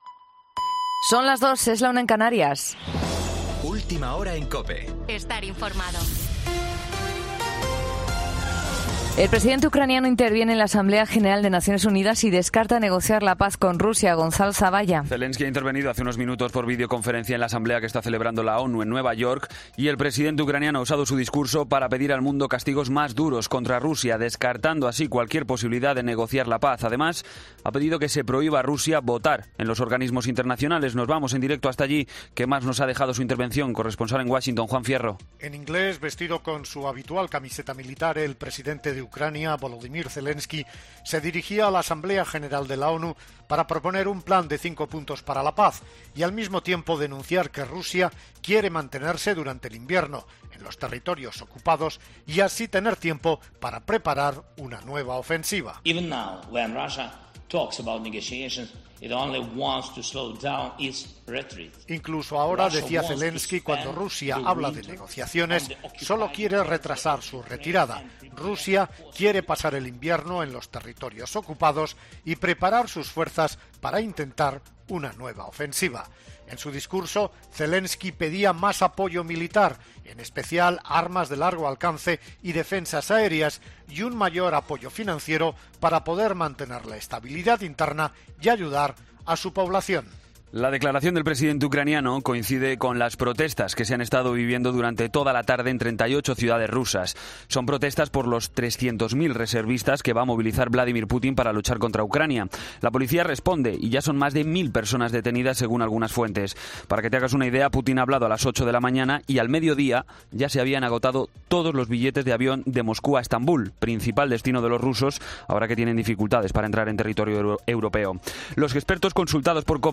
Boletín de noticias COPE del 22 de septiembre a las 02:00 hora